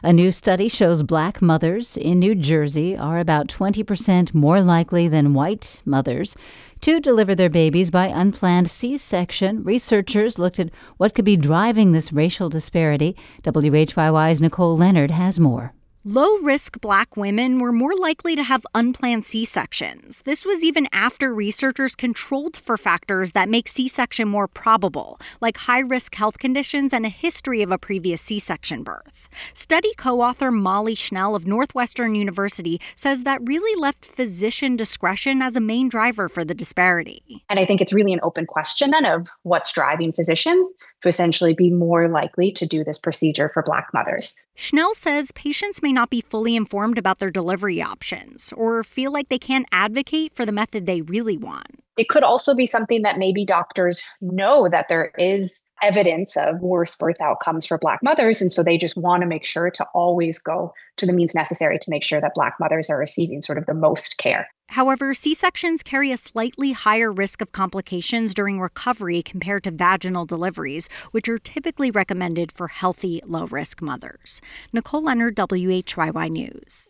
We also hear from a local volunteer who is working with Iraqi refugees stuck in visa limbo.